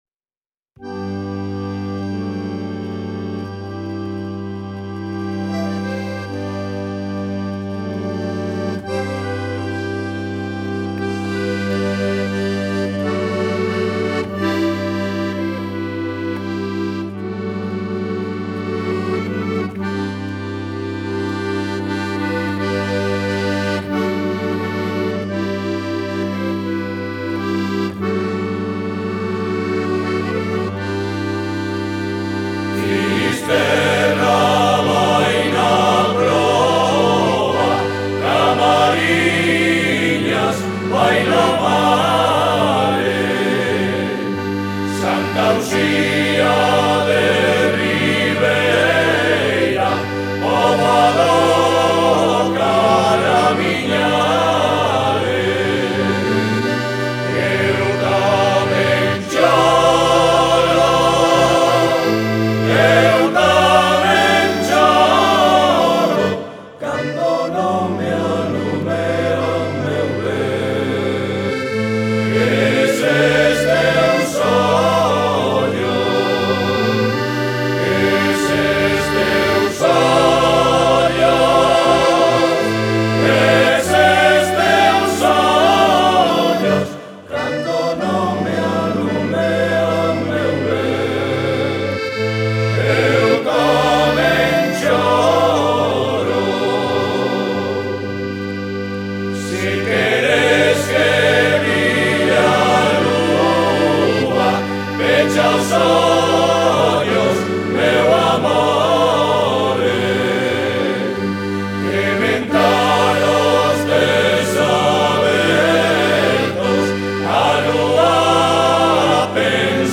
Folk / Tradicional / World music